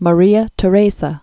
(mə-rēə tə-rāsə, -zə) 1717-1780.